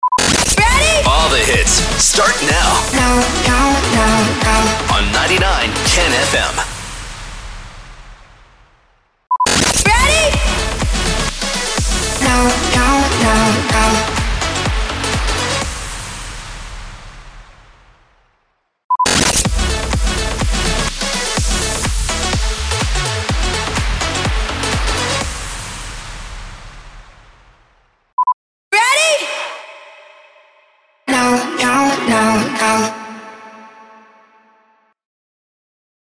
283 – SWEEPER – REJOIN
283-SWEEPER-REJOIN.mp3